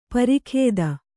♪ pari khēda